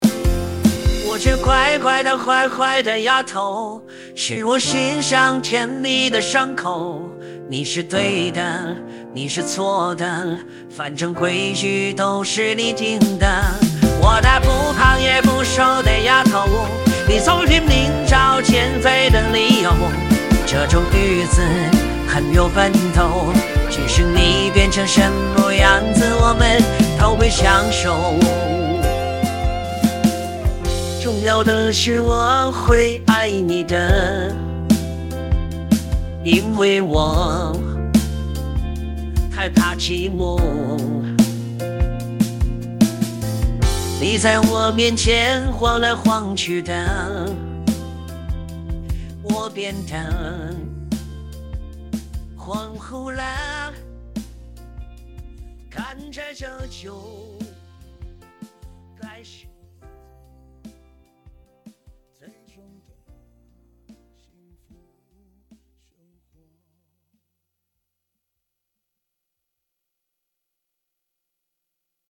翻唱音色